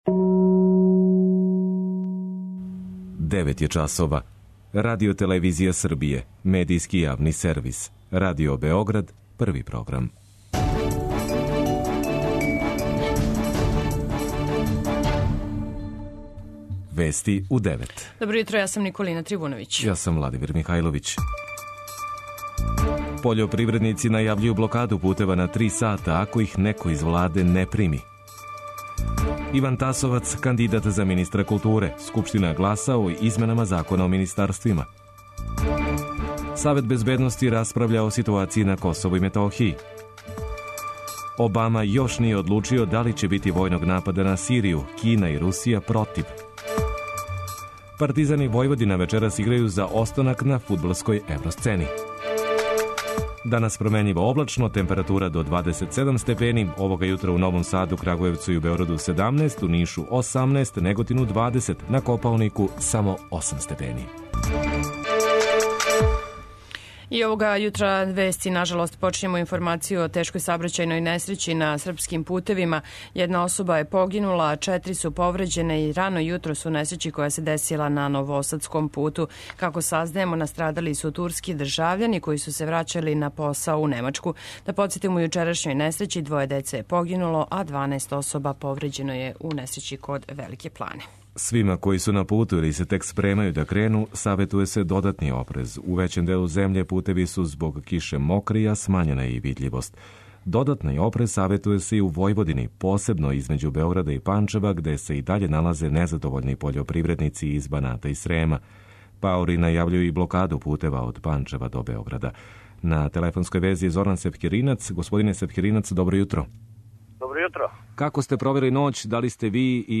Овога јутра у Новом Саду, Крагујевцу и Београду 17, у Нишу 18, Неготину 20, на Копаонику само 8 степени. преузми : 10.28 MB Вести у 9 Autor: разни аутори Преглед најважнијиx информација из земље из света.